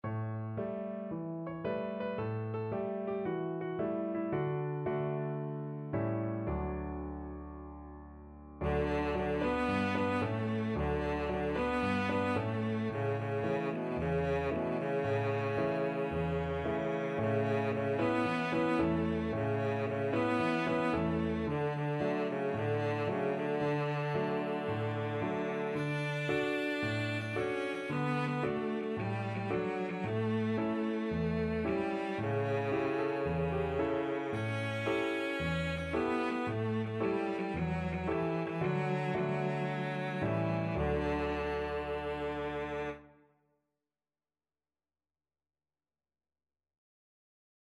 Traditional Trad. Oma Rapeti Cello version
Cello
4/4 (View more 4/4 Music)
Cheerfully! =c.112
D major (Sounding Pitch) (View more D major Music for Cello )
B3-D5
Traditional (View more Traditional Cello Music)